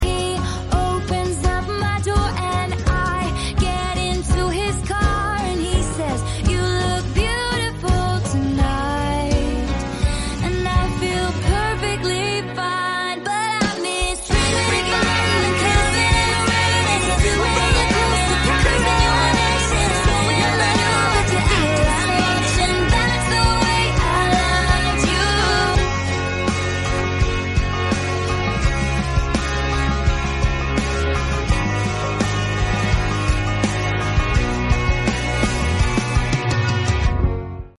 overlapped + instrumental edit audio